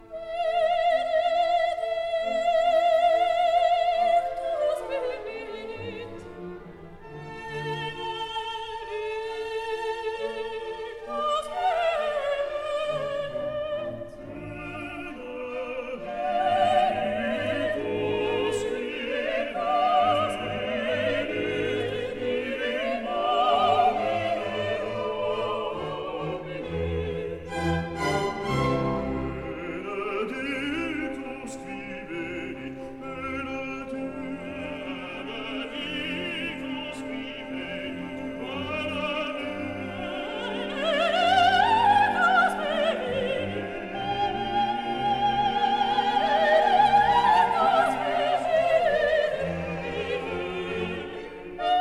soprano
mezzo-soprano
tenor
bass
1958 stereo recording